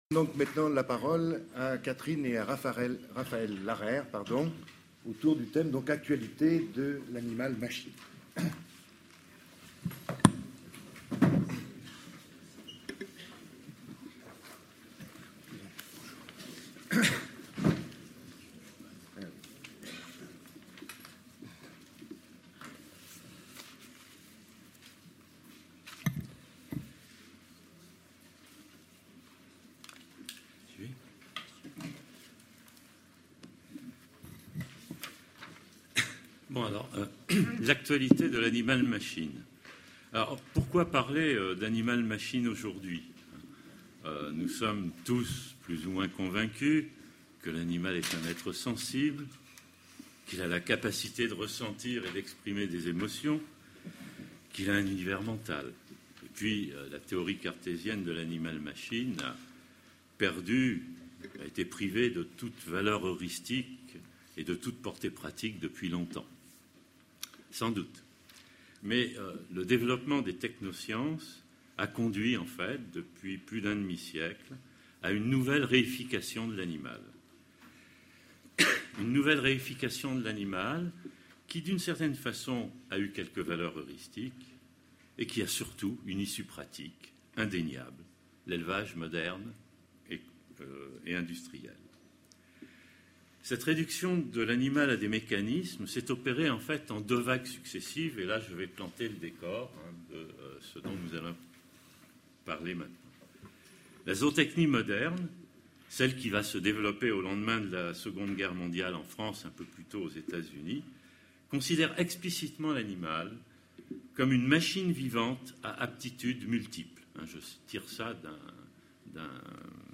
Colloque La représentation du vivant : du cerveau au comportement Session Pensée et identité